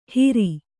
♪ hiri